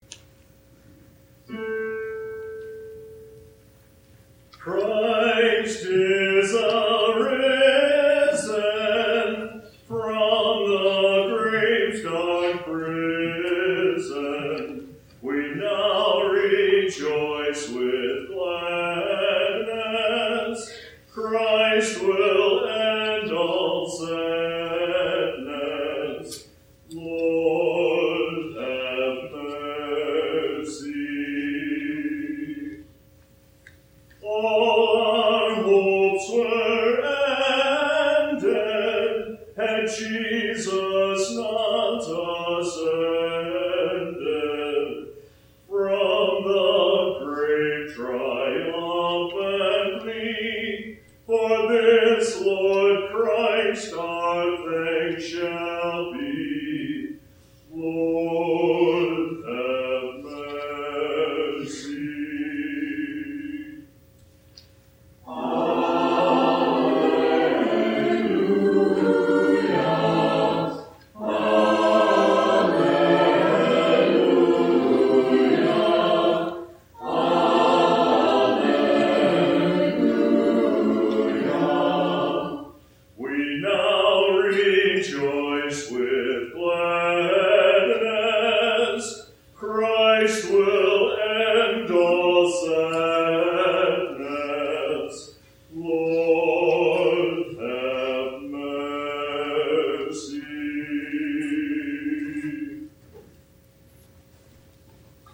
Senior Choir
Senior Choir Performances